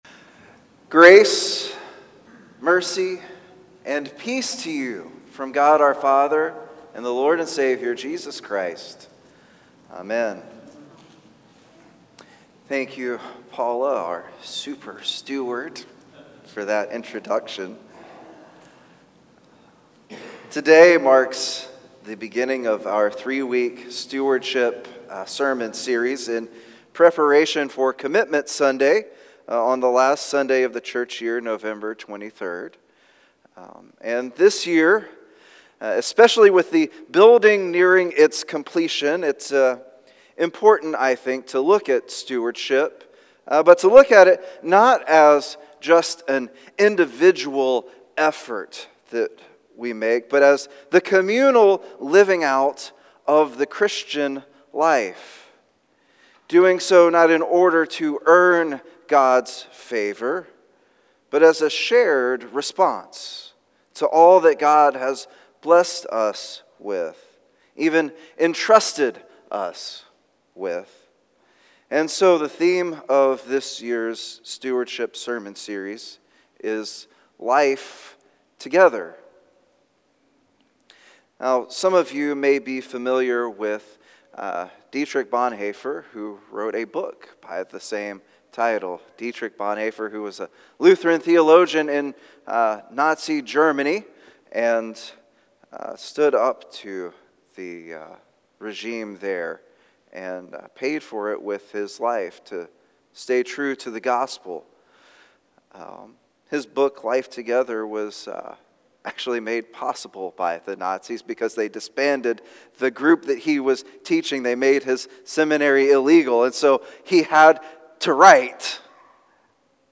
Service Type: Traditional and Blended Topics: stewardship